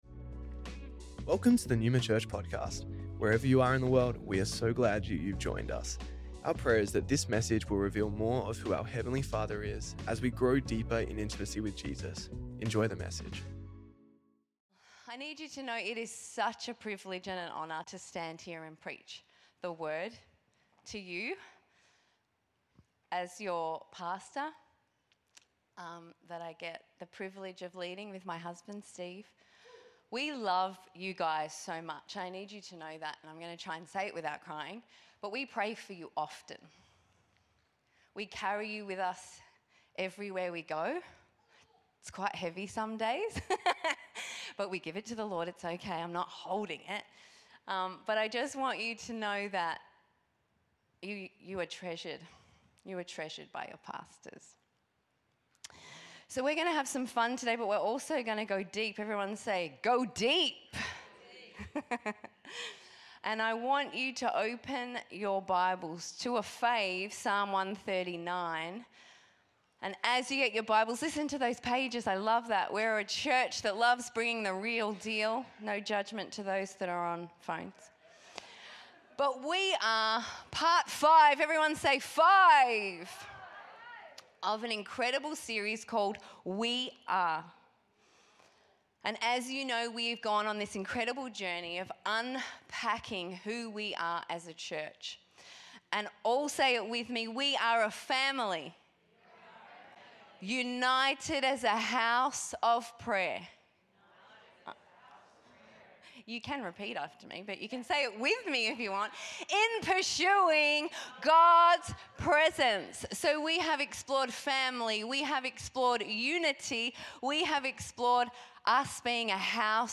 Neuma Church Melbourne South Originally Recorded at the 10AM Service on Sunday 30th March 2025